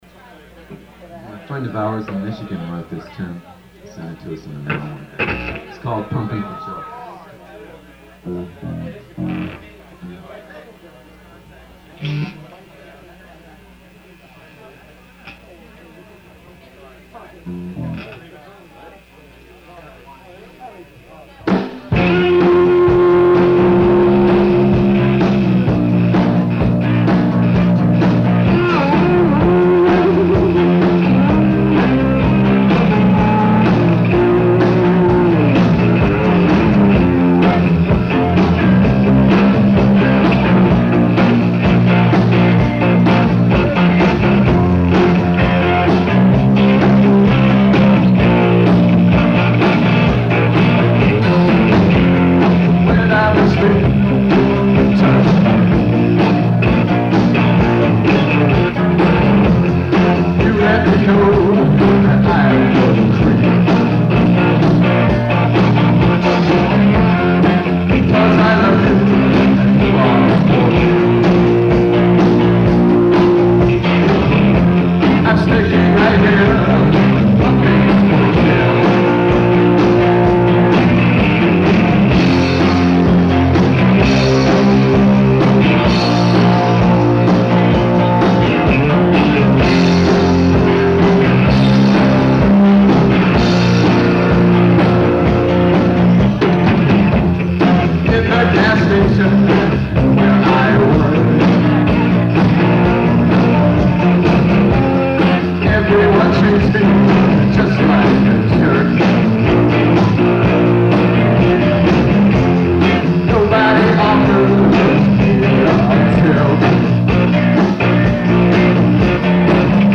Live Philadelphia